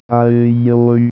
synthèse de la parole: enchainement de 5 voyelles du français
Example of speech synthesis by simulation of the vocal tract
5 connected vowels of the standard French :"a e i o u" ( orthographic transcription) [ a ø i o y] (phonetic transcription).
The original analog recording (1976, digitized in 2000) was numerically restored (december 2017) using Praat software.
- A 6 db/octave pre-emphasis from 500 Hz to compensate for loss of high frequencies due to aging of the magnetic tape.
- Low-pass filtering from 5 kHz ( attenuation at 5.5 kHz: 6 dbB; attenuation at 6 kHz: > 55 dB). (for these examples of synthesis the frequencies > 6 kHz are not relevant).
- The silence zones are zeroed (removing various noises and echos)